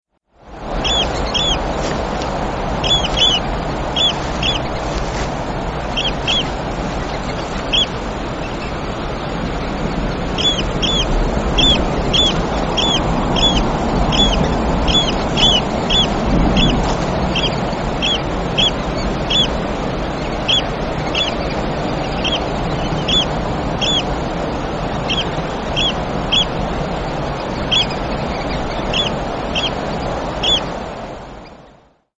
Oystercatcher incubating eggs
Tags: Oystercatcher Sounds Oystercatcher Oystercatcher Sound Oystercatcher clips Oystercatcher call